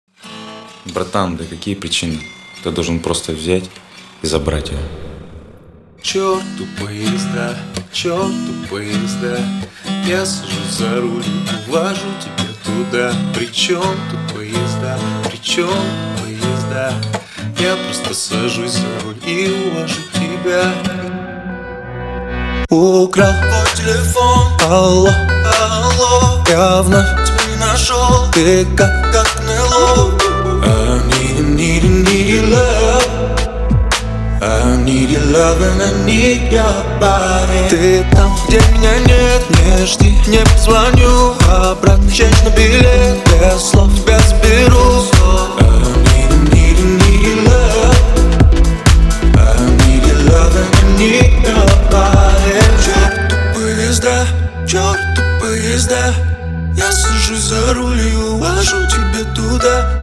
• Качество: 320, Stereo
поп
гитара
нарастающие
цикличные